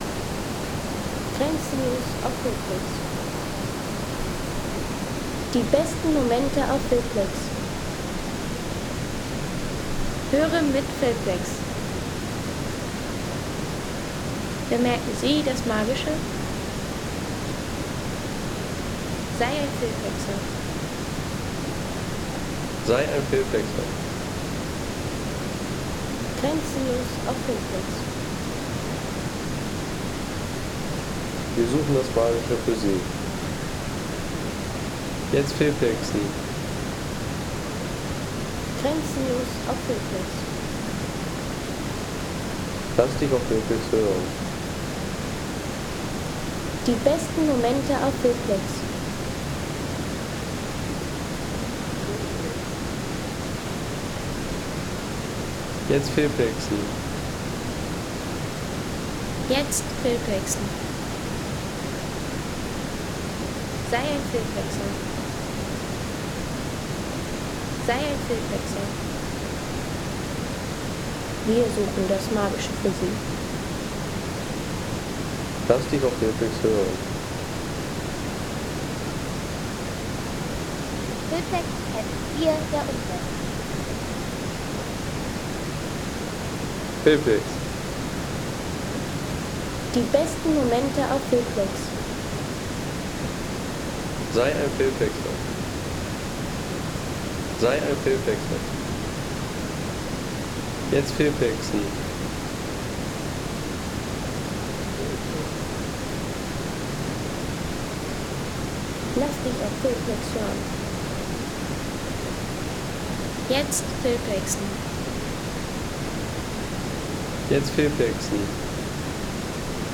Kategorien: Landschaft - Bäche/Seen